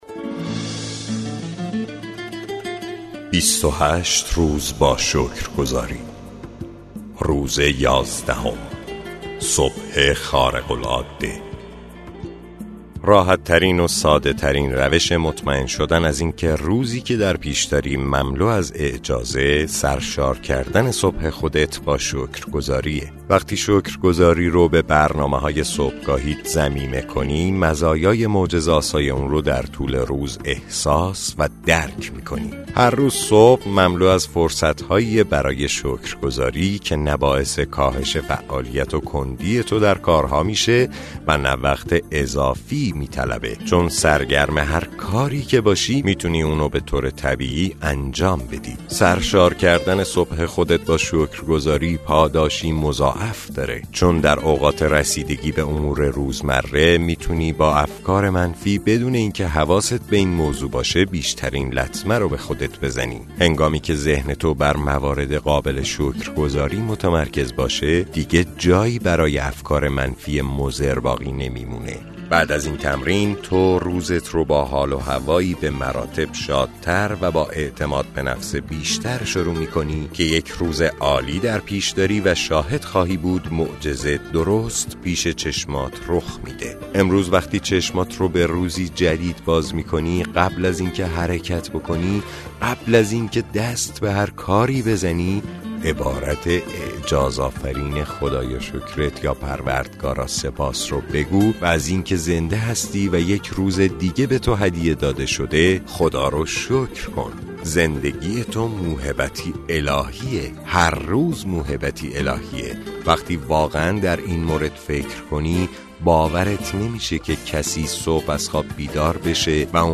کتاب صوتی معجزه شکرگزاری – روز یازدهم